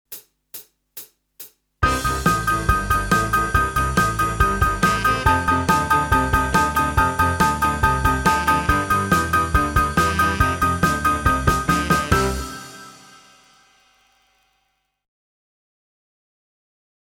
Méthode pour Piano - Piano ou Clavier